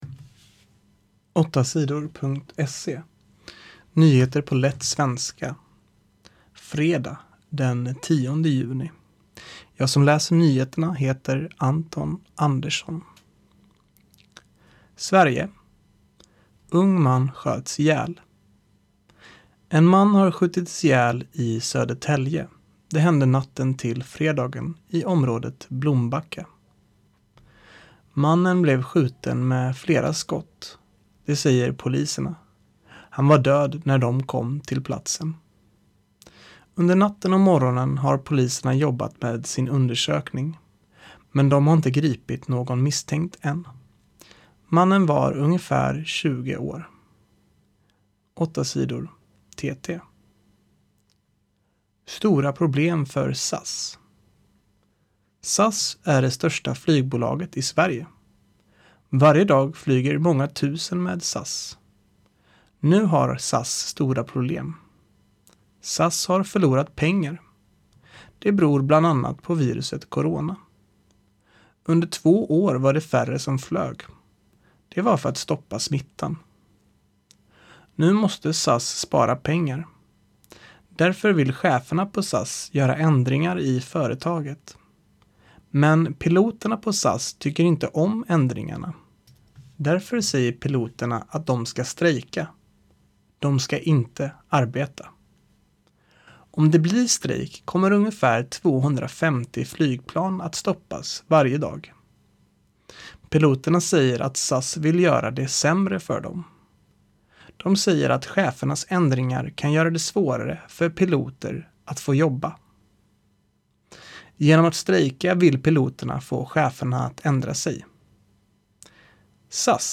Nyheter på lätt svenska den 10 juni